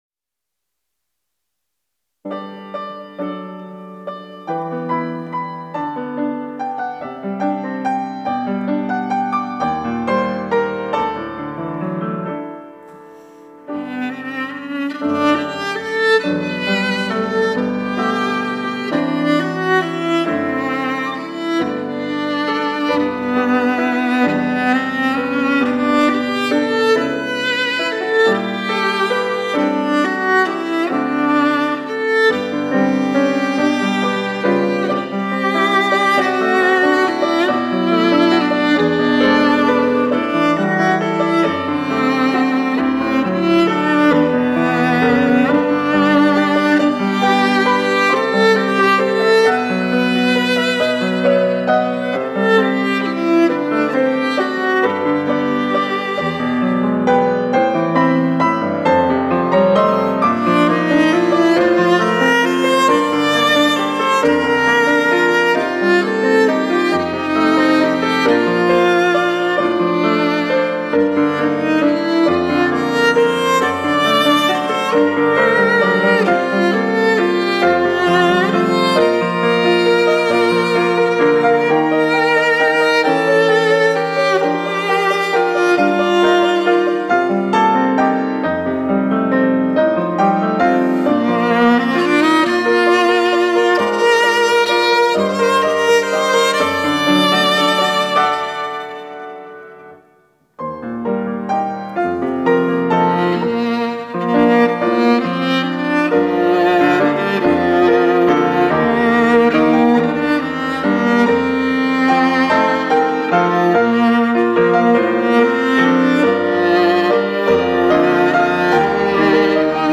특송과 특주 - 너 근심 걱정 말아라